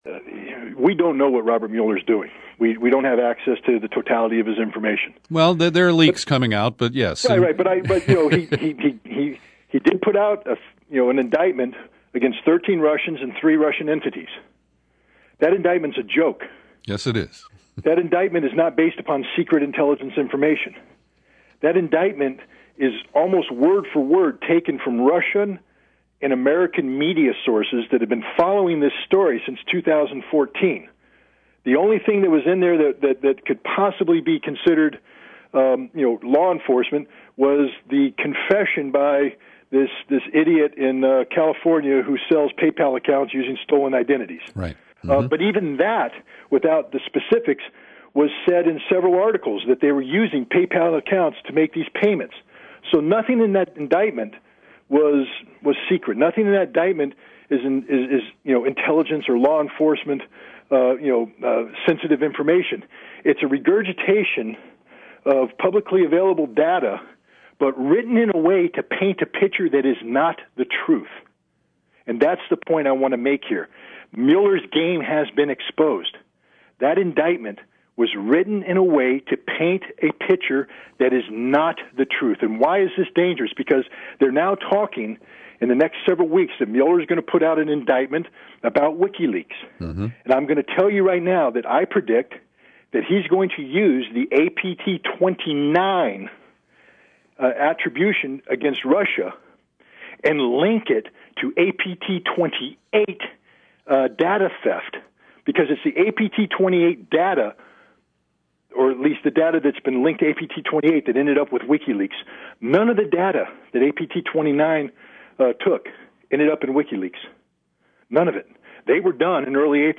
In-Depth Interview: Former Iraq Weapons Inspector Scott Ritter Offers Insight and Analysis on Russiagate